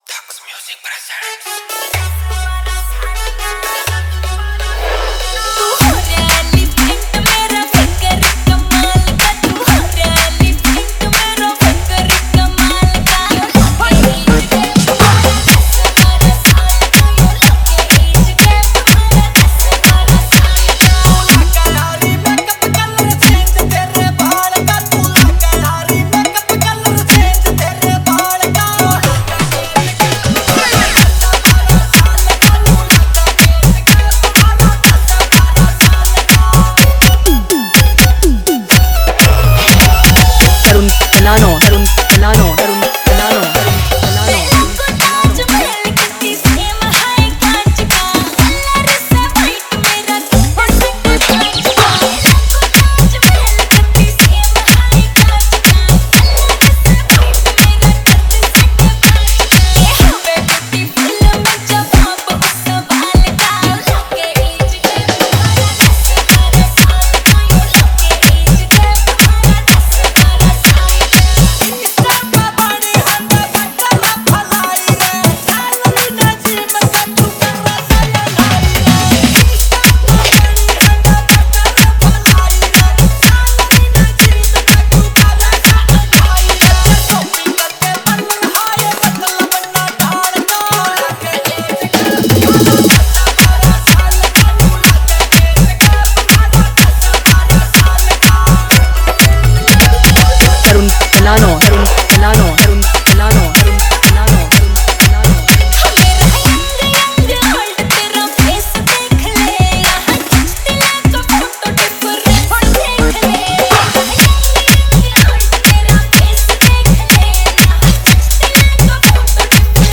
Haryanvi Remix Song